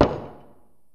gavel.wav